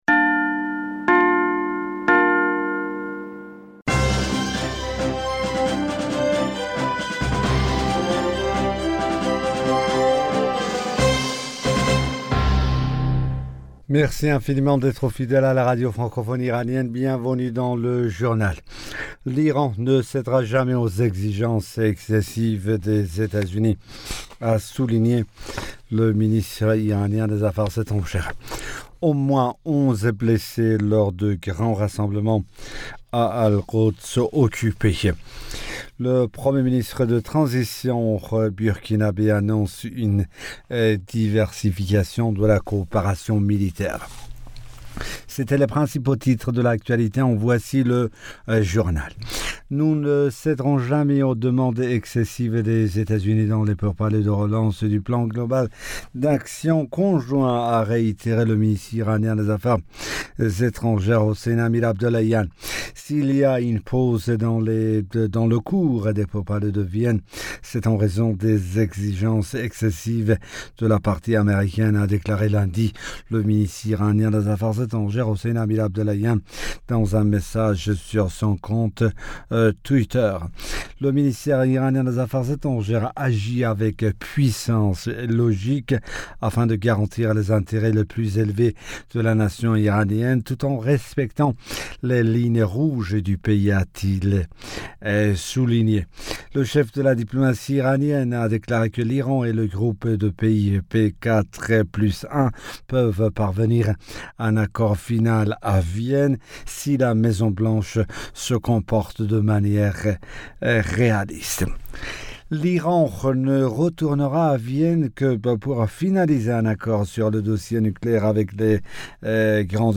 Bulletin d'information Du 05 Avril 2022